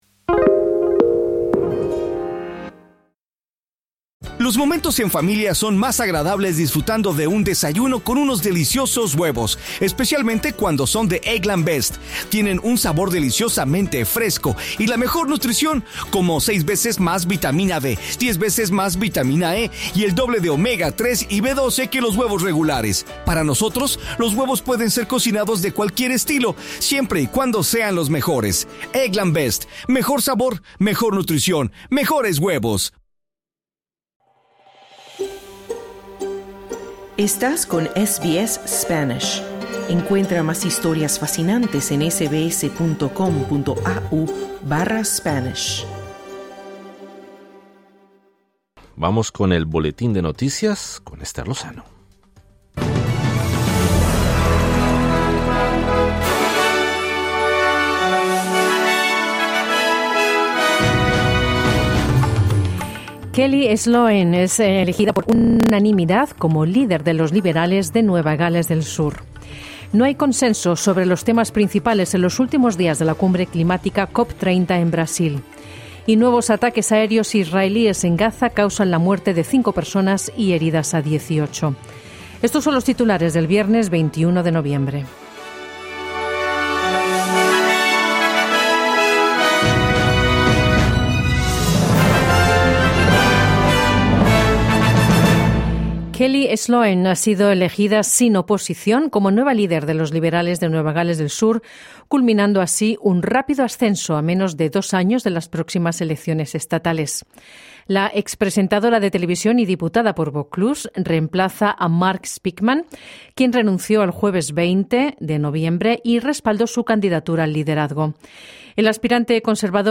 Noticias SBS Spanish | Falta de consenso en los últimos días de la cumbre climática COP30 en Brasil